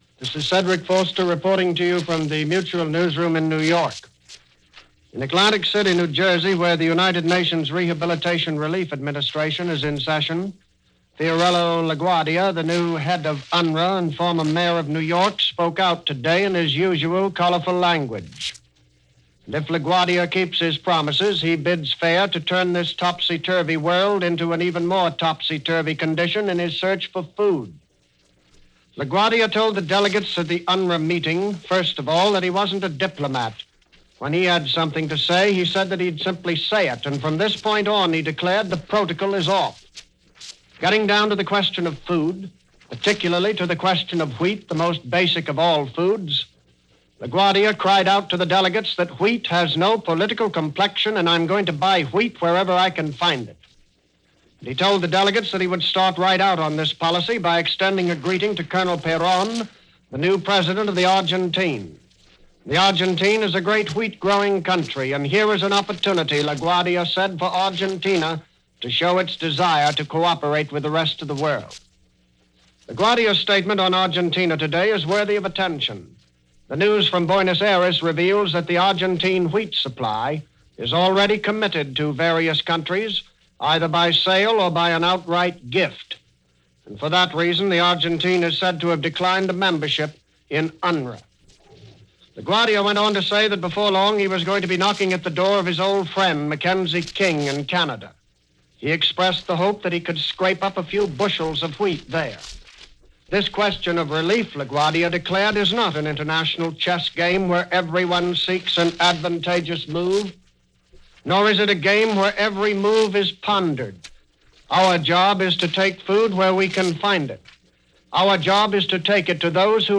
News for this day, reported on and commented by Cedric Foster of Mutual Broadcasting. The focus was on reconstruction – hunger and the displaced throughout Europe and how meeting those needs was going to be accomplished.